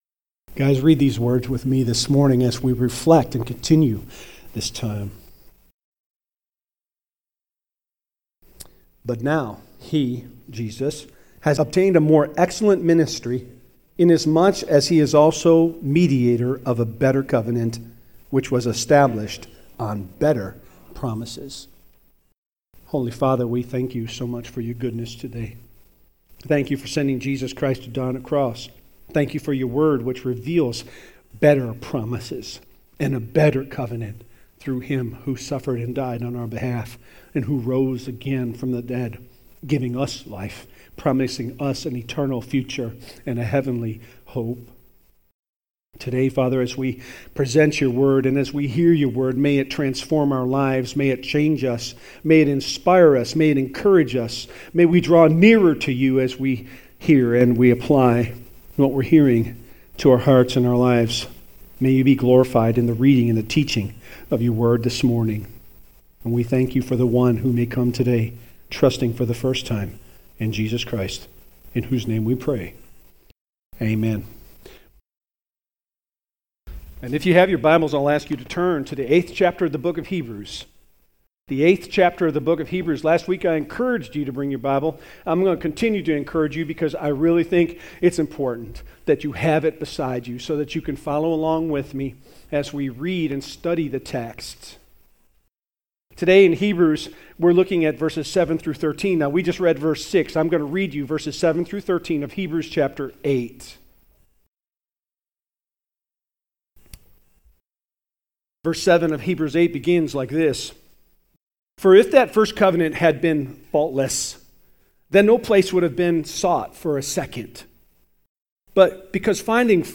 Hebrews 8:7-13 Service Type: Southern Baptist One man reflecting on God desire wrote